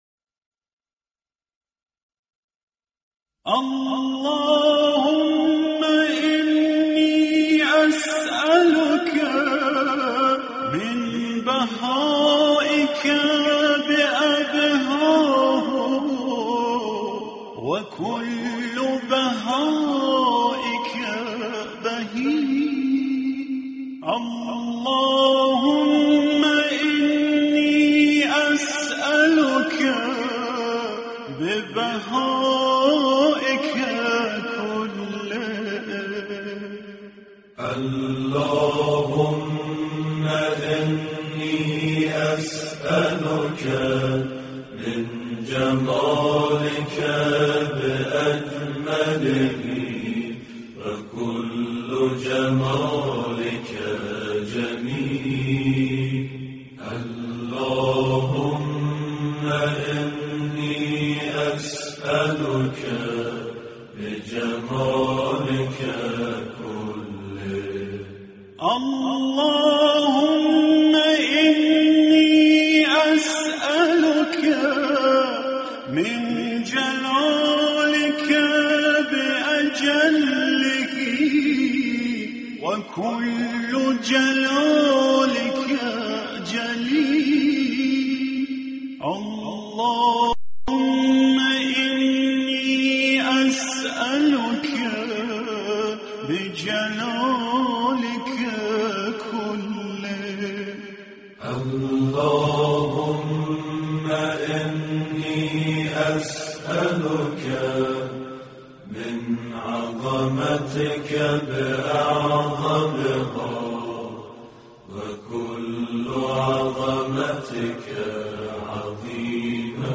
قرائت دعای سحر - محمد اصفهانی